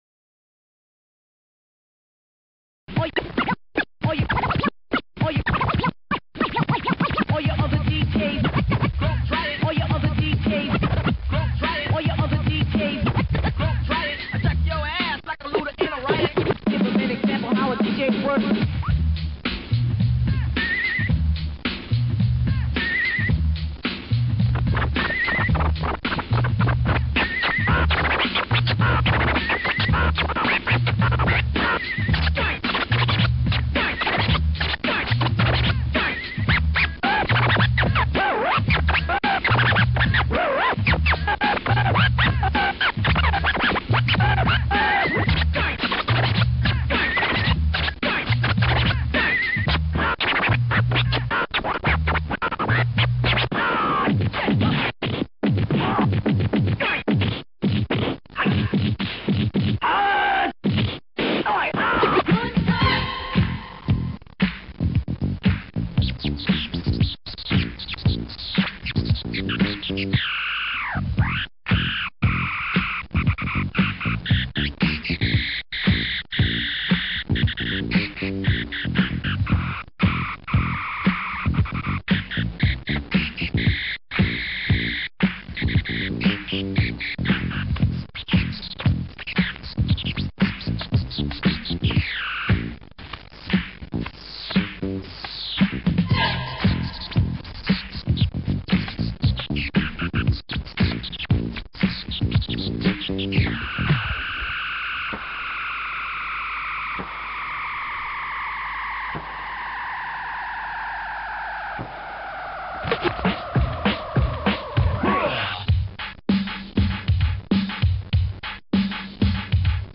two turntables: